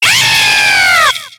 Cri de Zéblitz dans Pokémon X et Y.